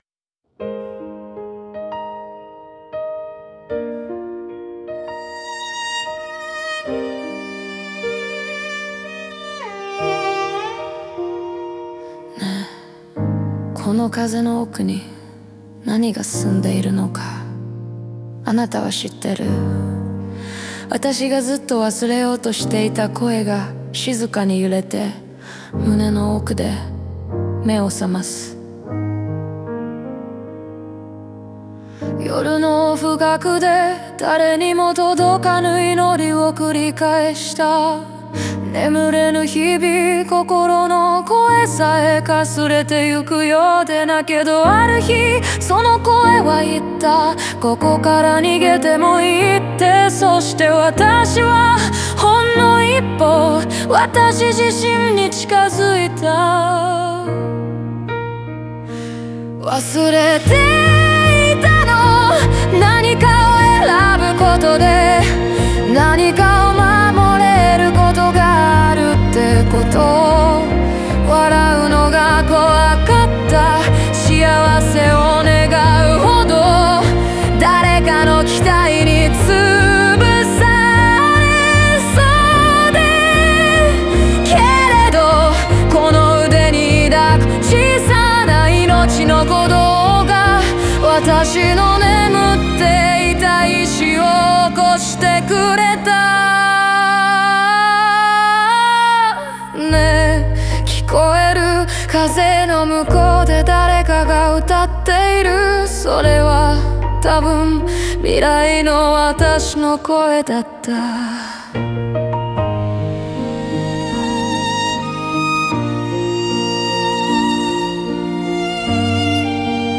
語りかけるような柔らかな歌声が、聴く者の心に静かに寄り添い、悲しみを抱えつつも未来へ踏み出す勇気を与える。